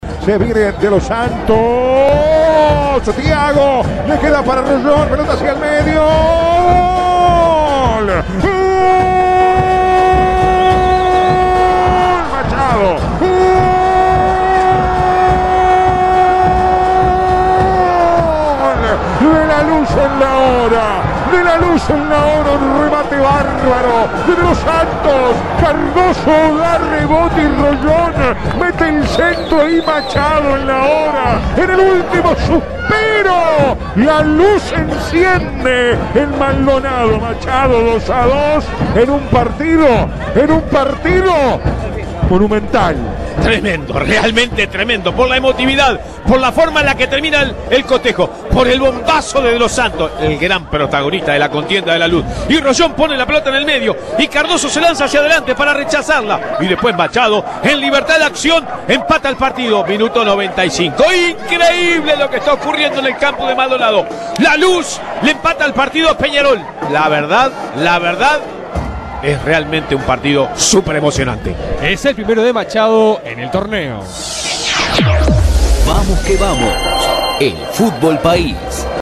El partido de locos entre merengues y carboneros en ma voz del equipo de VQV